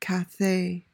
PRONUNCIATION: (ka-THAY) MEANING: noun: 1.